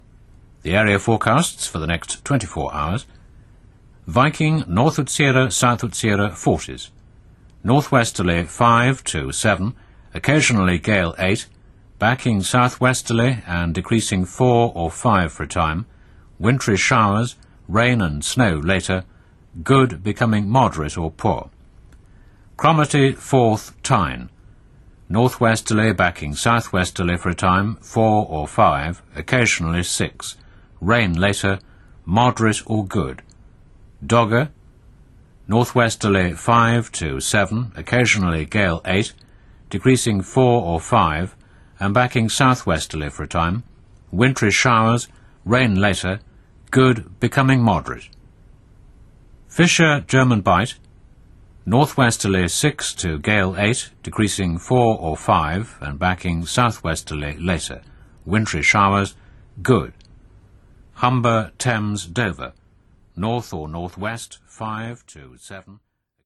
"The Shipping Forecast" a' caratterizzato anche dalla trasmissione radio in onde lunghe (attualmente via BBC Radio 4 sui 198 kHz) in modo tale da poter essere ascoltato senza difficolta', giorno e notte, da tutta la costa britannica indipendentemente dalle condizioni radio di ascolto (ovviamente trasmissione da non confondere con quelle emesse dalle stazioni costiere in VHF e MF). Bollettino che inizia sempre con "And now the Shipping Forecast, issued by the Met Office on behalf of the Maritime and Coastguard Agency at xxxx today." con una lunghezza massima del testo di 370 parole.
Trasmissione conosciuta anche da chi non e' direttamente interessato al servizio: un ascolto "notturno" quasi ipnotico grazie ai nomi dei tratti di mare interessati, alla cadenza vocale con criteri ben precisi in essere ormai da decenni che fanno correre la fantasia degli ascoltatori.
Shipping_Forecast.mp3